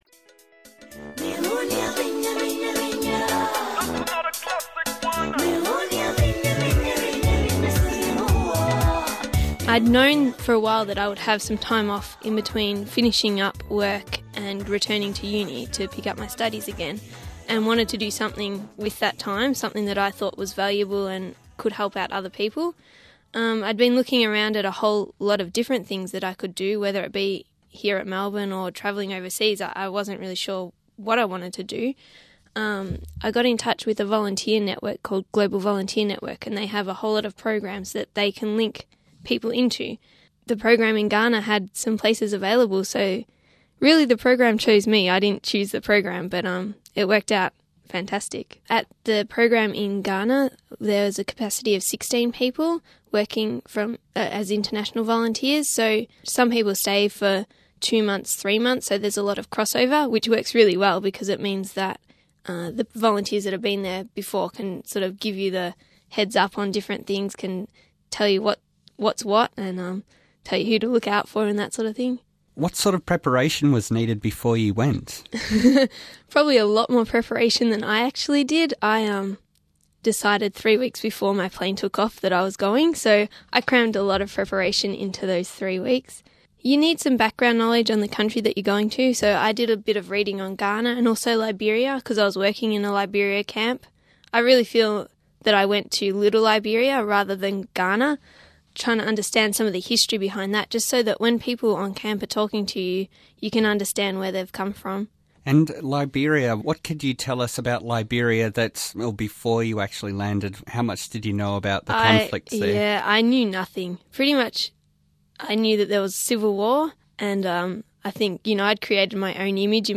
In today’s Audio Postcard